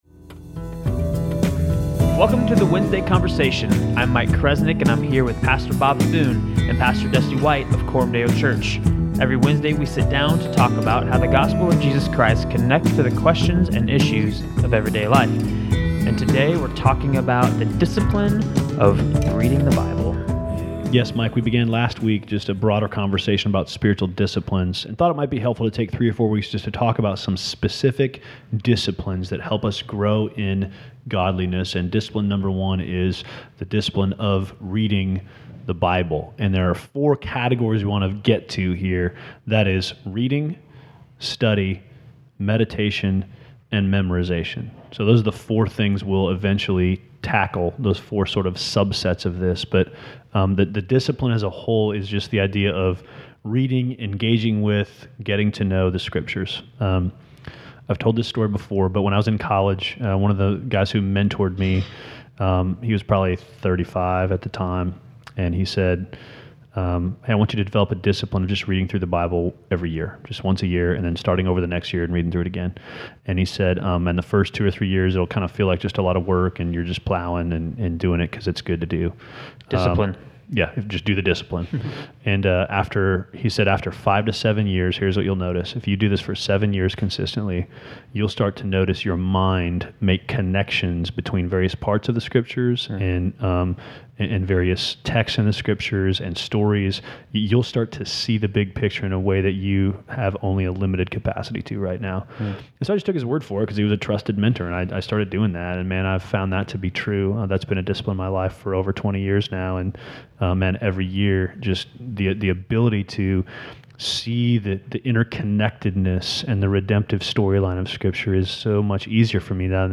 We start the New Year off right with a new podcast studio (echoes and construction sounds are free...) and a new topic of conversation, Spiritual Disciplines.